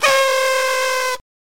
Звуки праздничной дудки
Звук веселой дудочки с детского Дня рождения